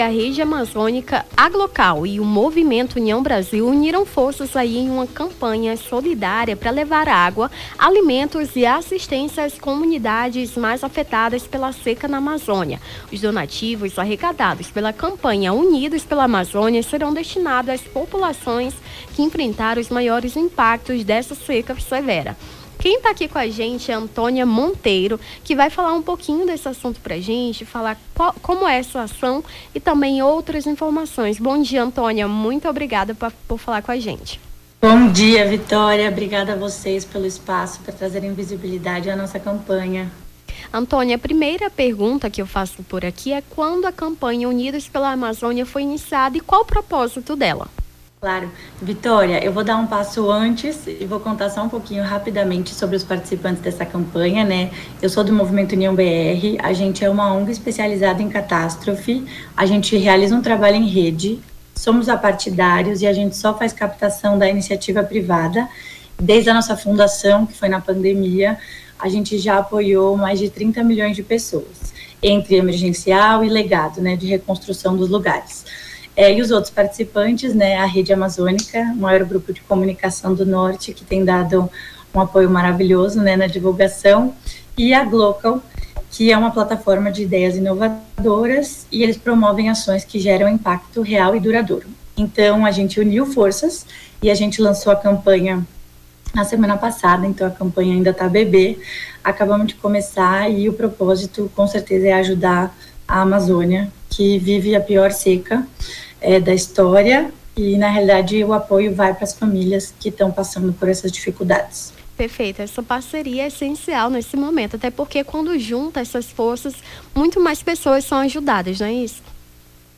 Nome do Artista - CENSURA - ENTREVISTA CAMPANHA UNIDOS PELA AMAZÔNIA (09-12-24).mp3